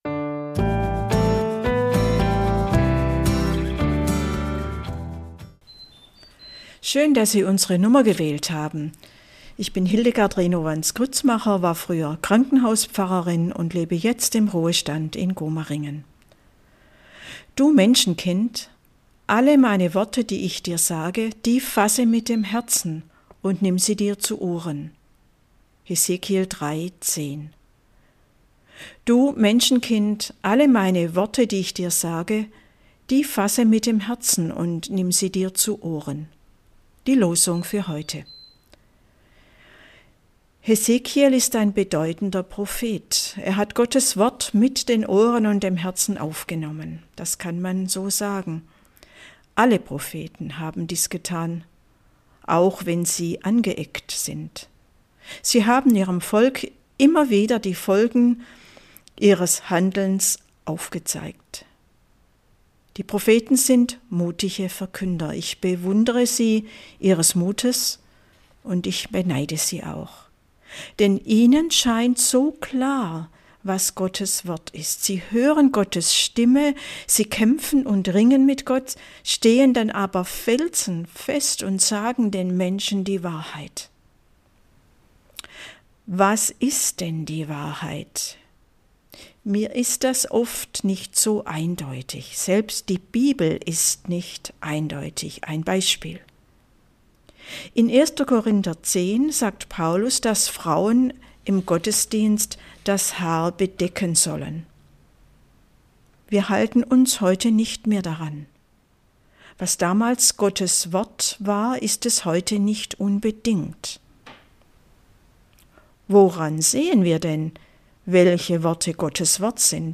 Andacht zum Wochenspruch